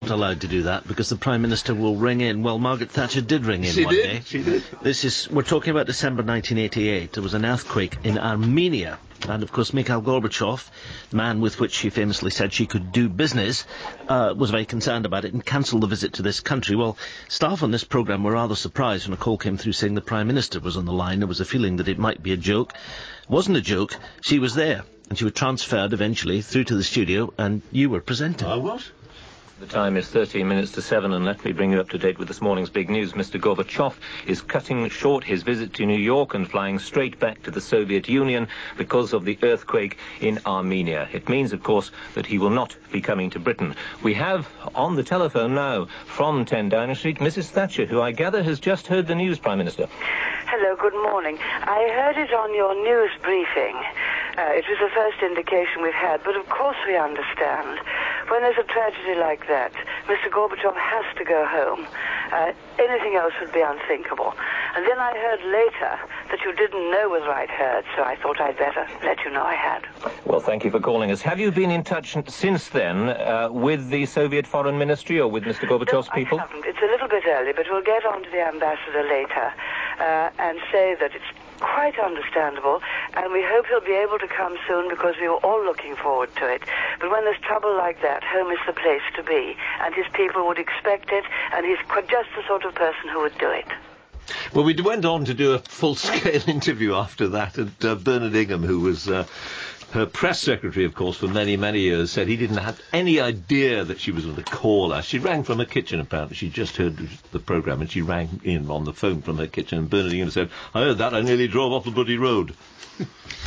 In this audio, hear the original call, surrounded by contemporary commentary from Humphries and Naughtie on the day following the death of Baroness Thatcher, in 2013.